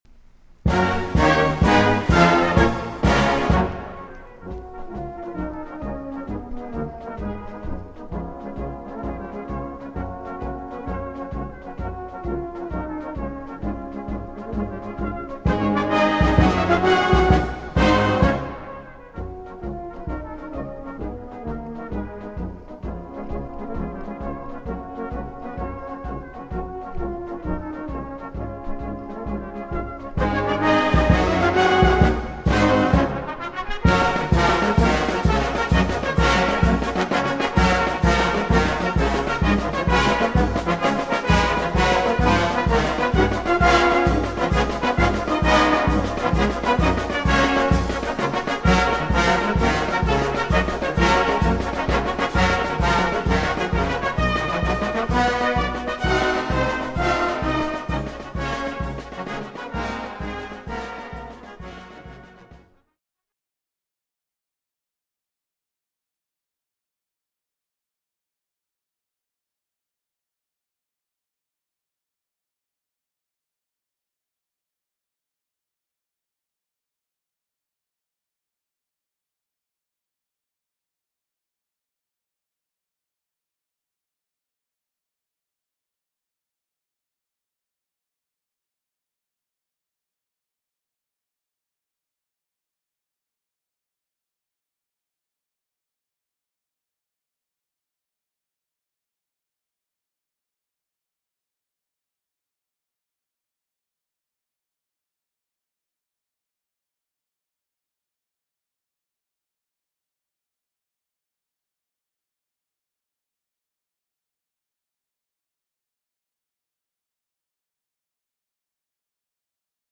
Brass band version.